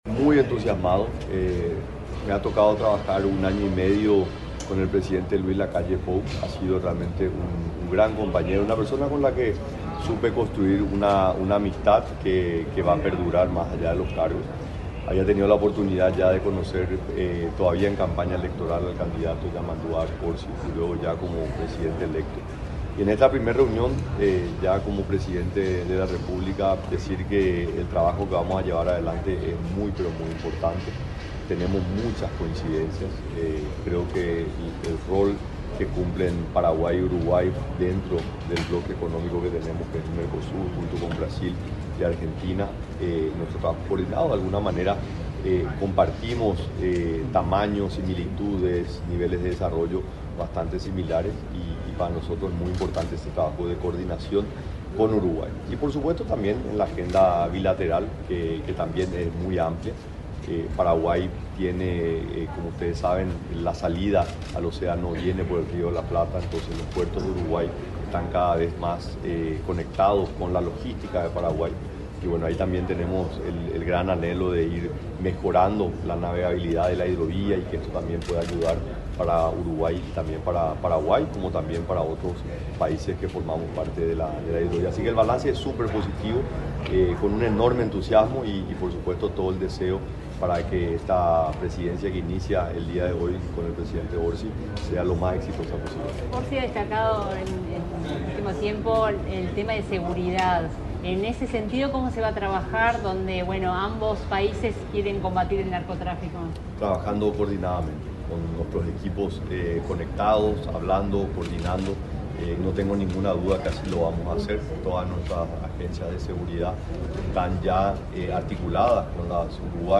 Declaraciones del presidente de Paraguay, Santiago Peña, a la prensa
Este domingo 2, el presidente de Paraguay, Santiago Peña, dialogó con la prensa a la salida del Palacio Estévez, donde se reunió con el presidente